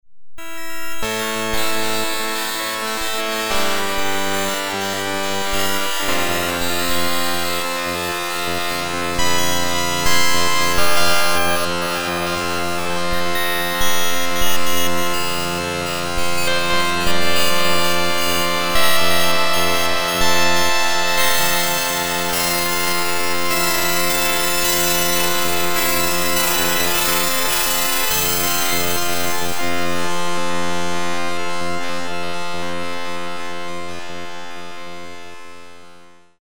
Organg is an electronic (transistor) organ that sounds a bit “broken”.
organg_demo.mp3